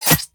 CosmicRageSounds / ogg / general / combat / weapons / hit3.ogg
hit3.ogg